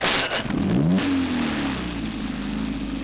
ignition.mp3